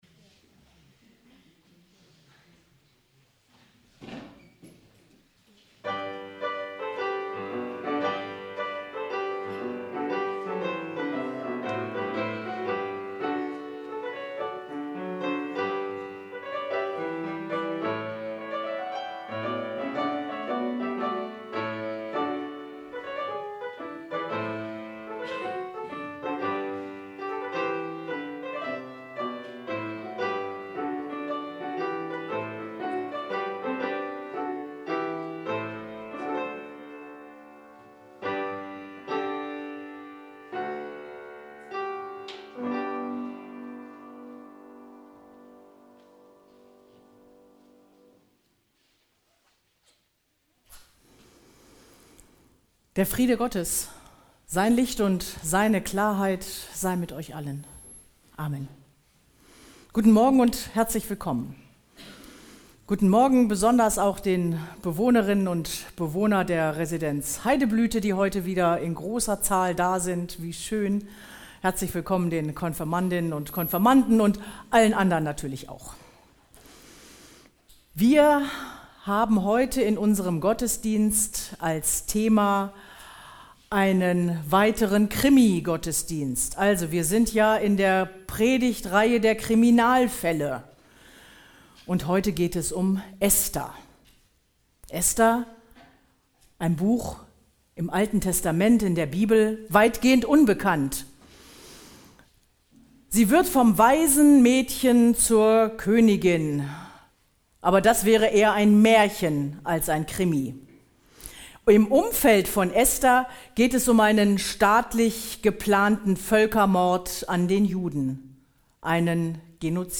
Gottesdienst - 01.02.2026 ~ Peter und Paul Gottesdienst-Podcast Podcast